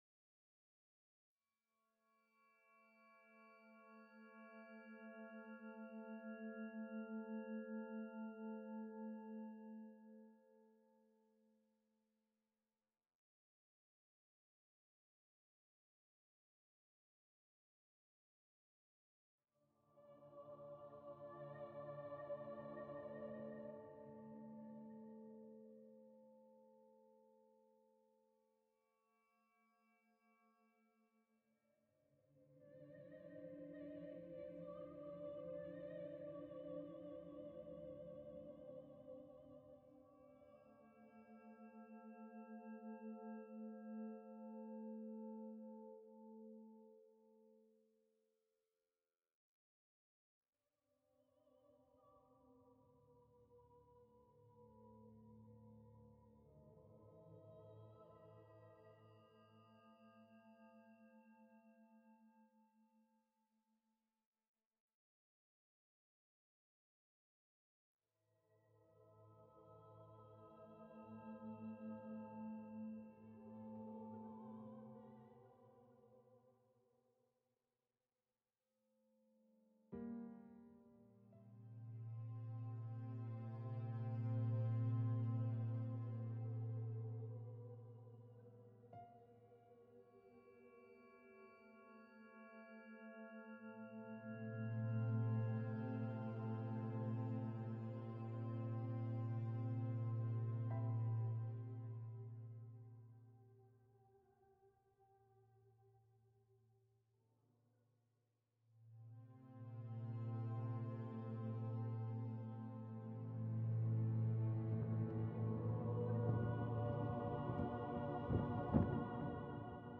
Duration – 12’45” Recording – 2 channel version